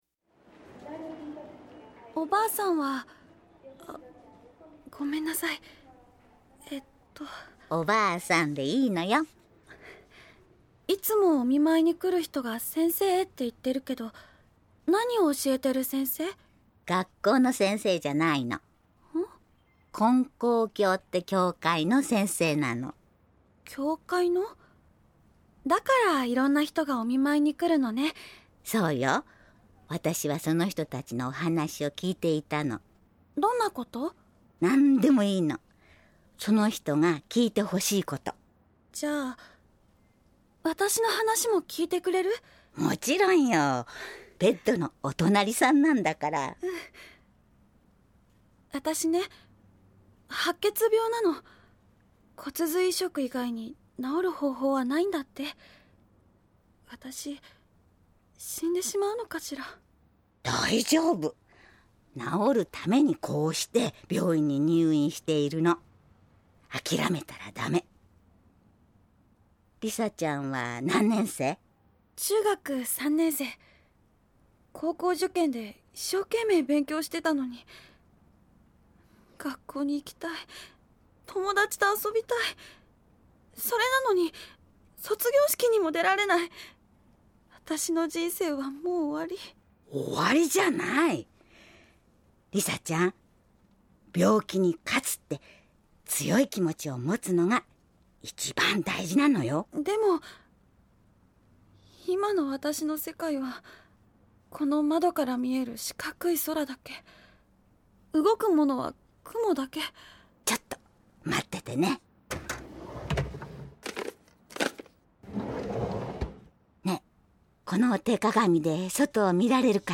ラジオドラマ「ようお参りです」最終回「ひとりじゃない」
・教会の先生（70歳・女性）